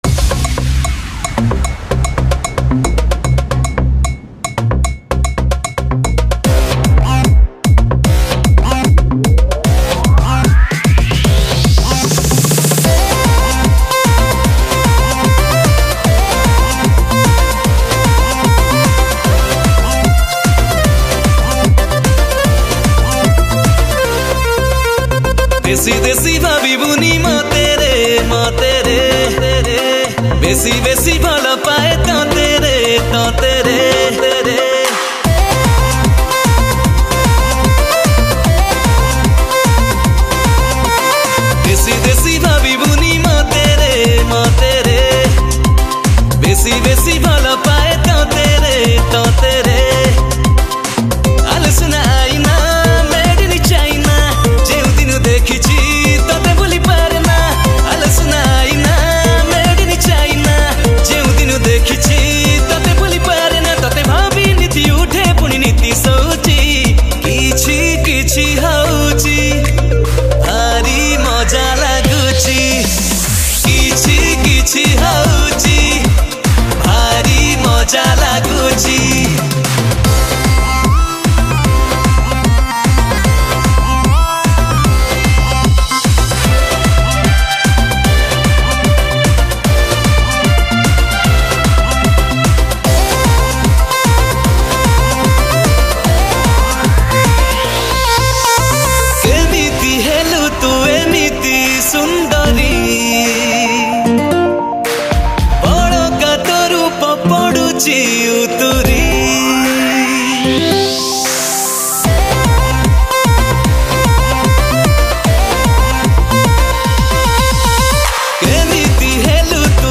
Odia Dance Song